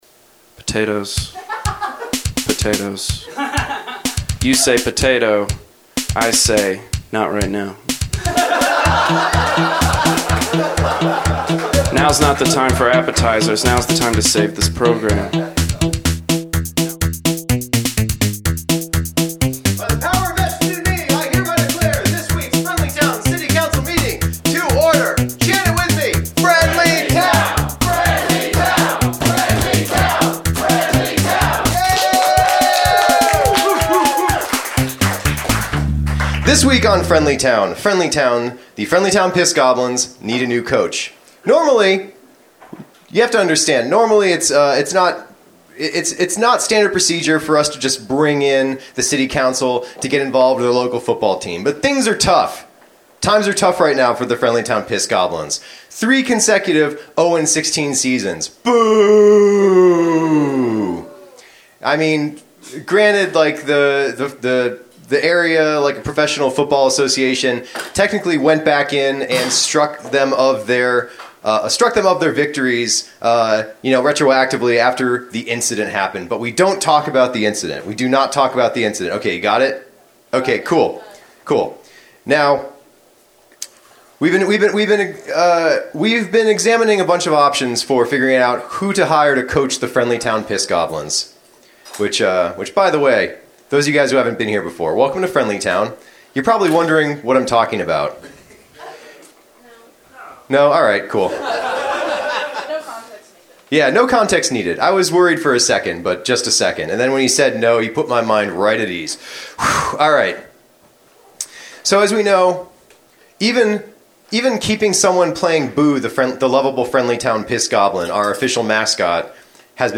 OH WHY CAN’T IT BE 1998 AGAIN WHY Recorded Live at the Pilot Light December 4, 2017, Knoxville TN.